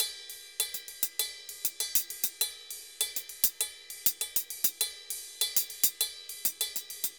Ride_Salsa 100_1.wav